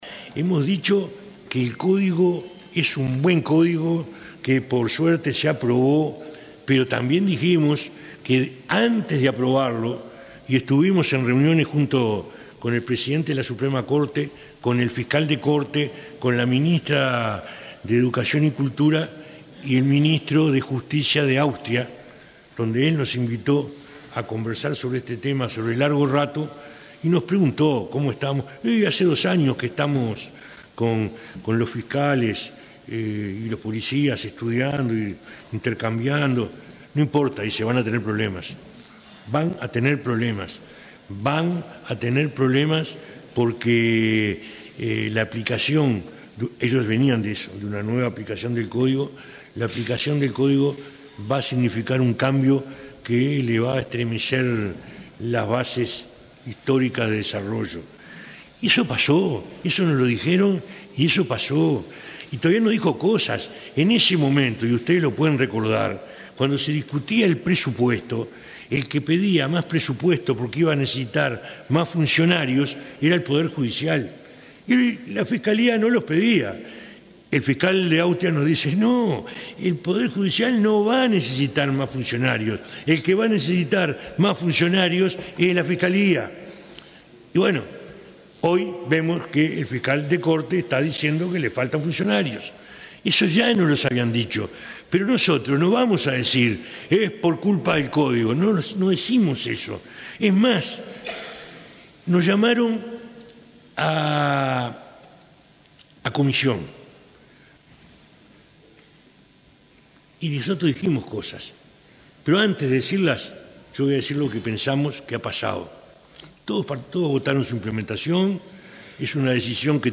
El ministro del Interior, Eduardo Bonomi, dijo en el Parlamento que es necesario ajustar el nuevo Código Procesal Penal que incluye la posibilidad de conducir a un detenido a la justicia para indagatoria sin que medie orden fiscal; que para los reincidentes la prisión preventiva deba ser preceptiva y que en caso de acuerdo para reducción de la pena, el imputado no pueda acceder a libertad anticipada sobre la pena acordada.